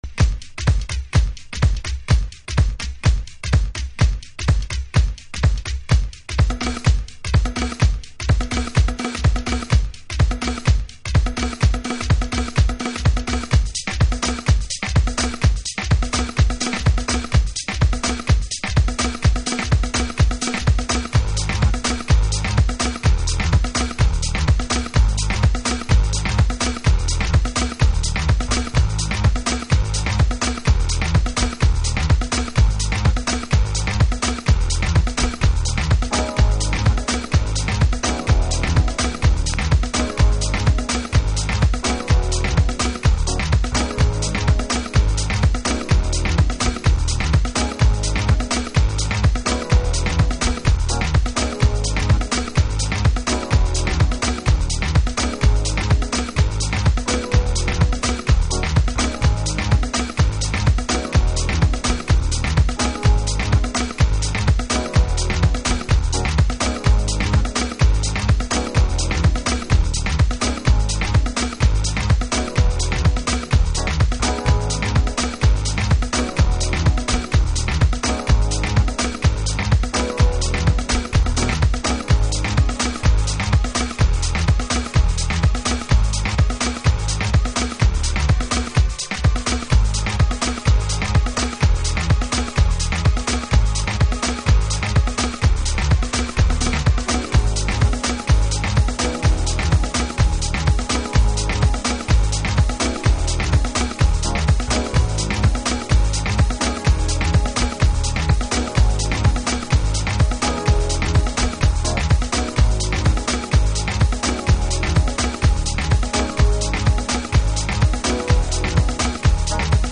House / Techno
Instrumental